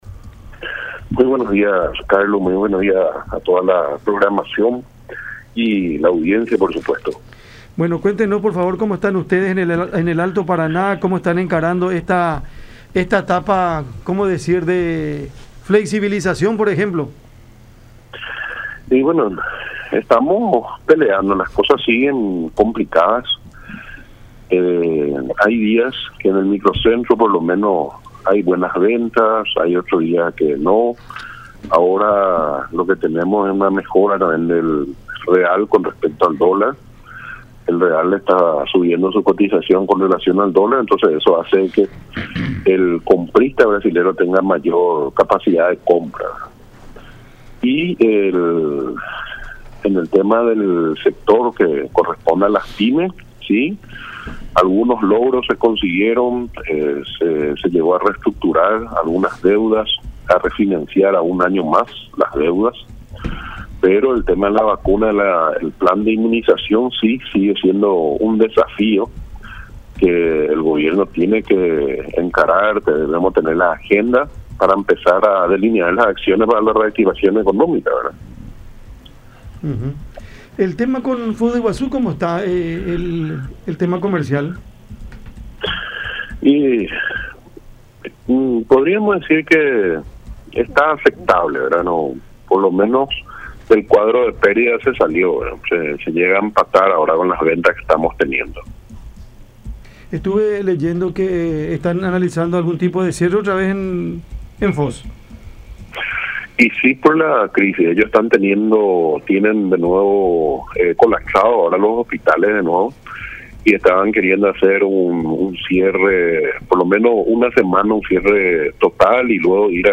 en conversación con el programa Cada Mañana a través de La Unión.